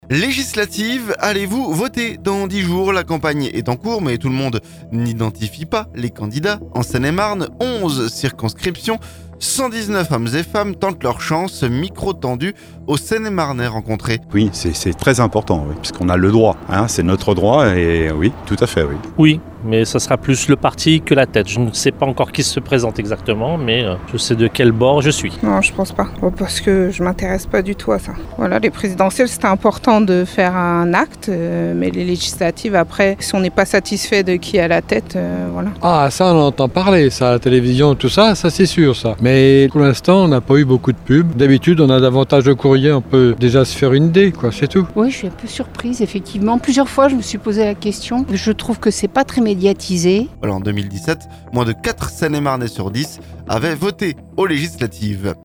La campagne est en cours, mais tout le monde n'identifie pas les candidats en Seine-et-Marne... 11 circonscriptions et 119 hommes et femmes tentent leur chance. Micro tendu aux seine et marnais rencontrés, alors qu'en 2017 moins de quatre seine et marnais sur dix avaient voté aux Législatives.